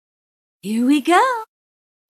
Description Daisy select character voice